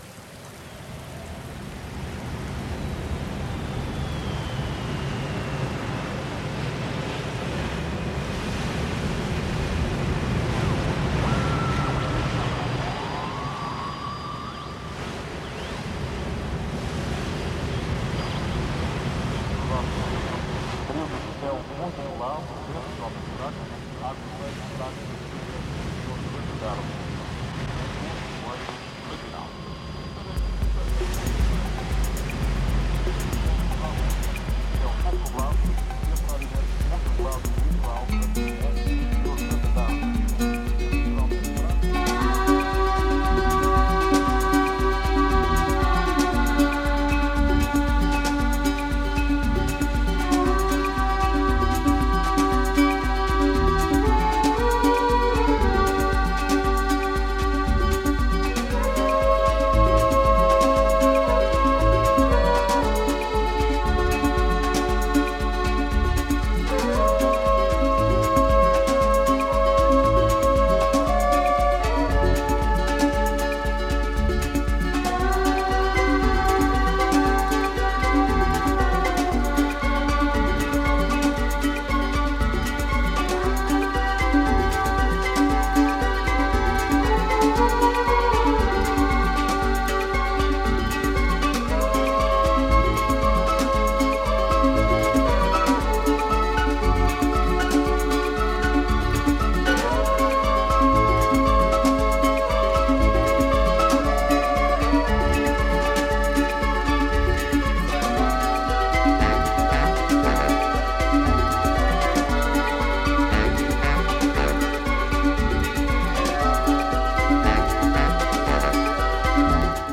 神秘的で土着的なアンビエント～ニュー・エイジな世界が◎！
民族音楽を経由したミニマルなアプローチが◎！